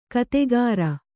♪ kategāra